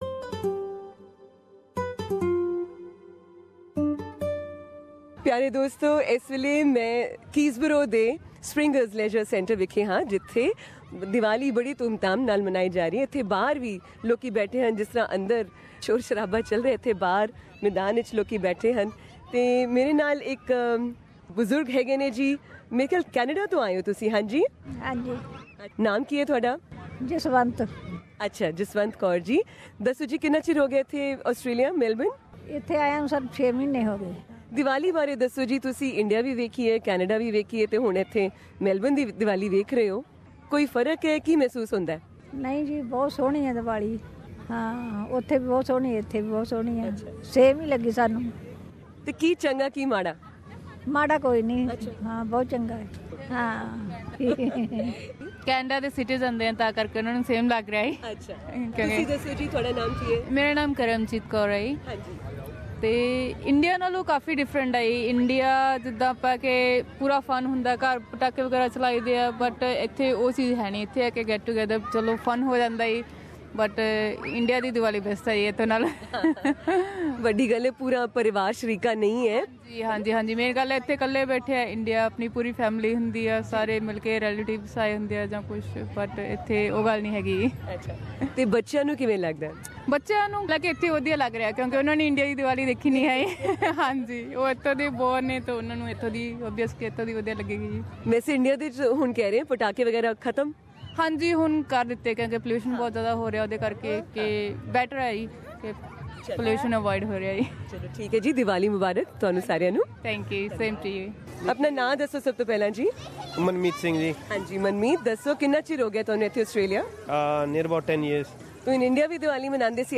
Hear from Victoria's Shadow Minister for Multicultural Affairs, Inge Peulich, and from other Melburnians attending the the Diwali Fair at Keysborough on Sucnday Oct 15, about what celebrating Diwali in Australia means to them....
Inge Peulich, the Shadow Minister for Multicultural Affairs in Victoria, celebrating Diwali in Melbourne on Oct 15, 2017 Source: SBS Punjabi